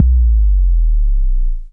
KICK177.wav